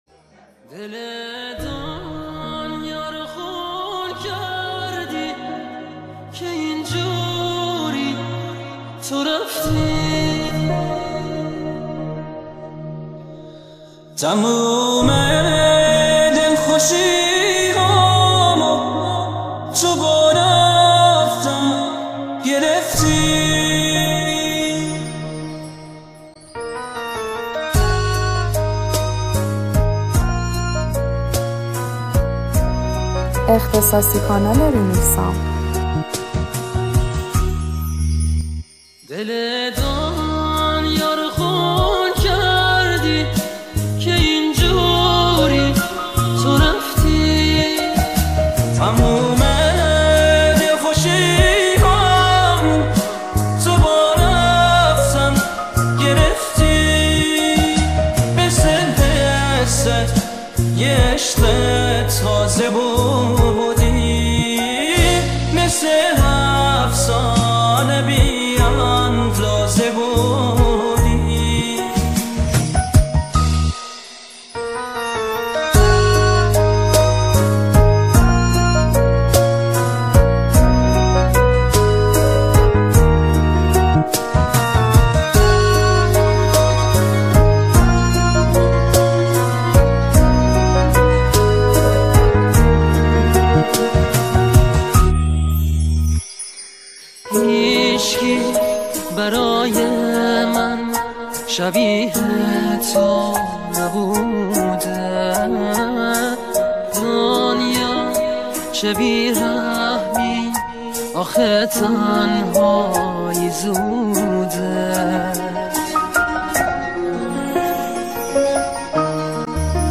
پاپ ایرانی